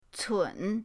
cun3.mp3